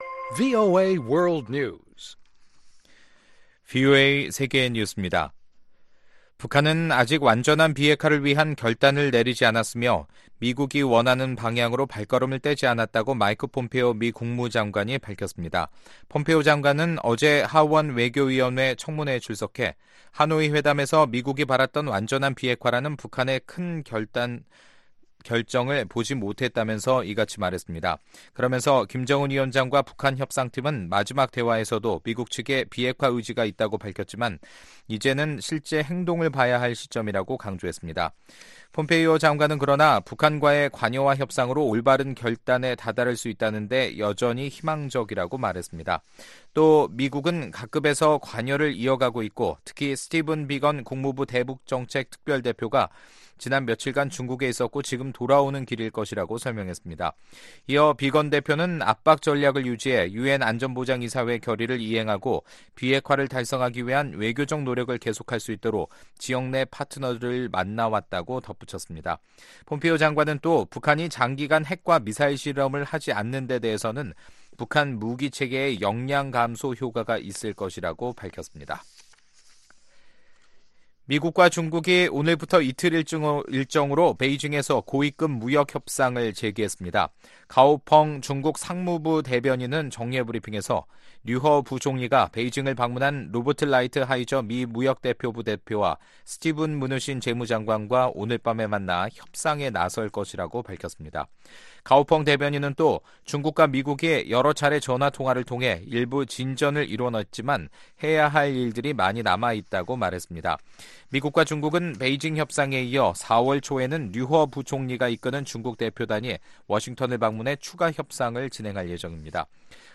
VOA 한국어 간판 뉴스 프로그램 '뉴스 투데이', 2019년 3월 28일 2부 방송입니다. 마이크 폼페오 미국 국무 장관은 북한이 아직 미국이 원하는 방향으로 발을 떼지 않았다고 밝혔습니다. 유엔 대북 제재위원회는 전문가 패널 관계자가 미의회 청문회에 출석해 북한의 제재회피 실상을 낱낱이 공개했습니다.